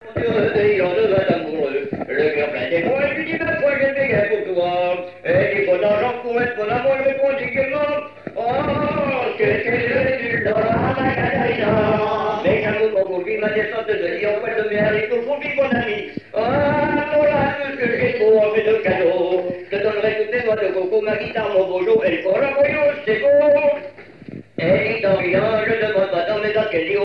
Genre strophique
collectage pour le groupe le Vir'Coët
Pièce musicale inédite